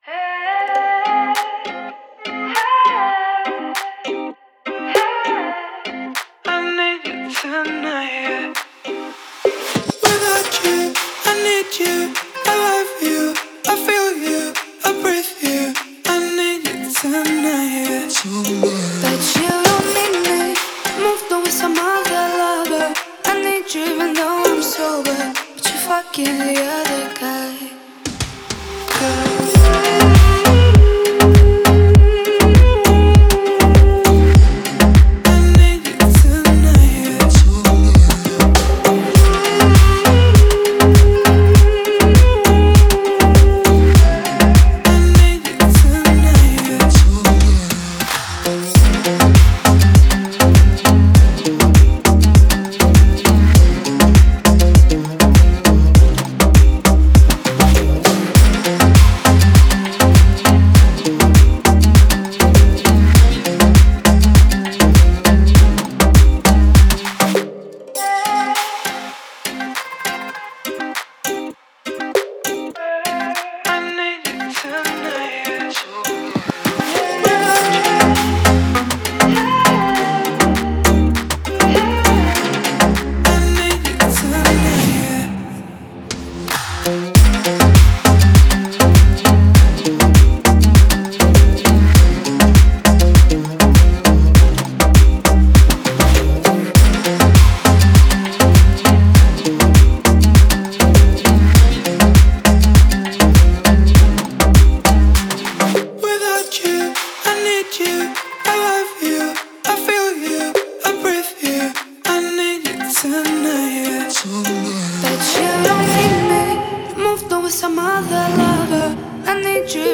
это яркий трек в жанре поп с элементами электронной музыки